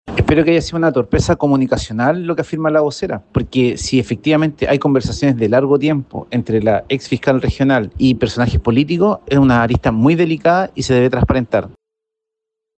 En la misma vereda, el diputado Jaime Araya (IND-PPD) señaló que espera que las declaraciones de la próxima vocera se hayan debido a un desliz comunicacional porque, de lo contrario, las conversaciones previas a la designación deberán ser transparentadas.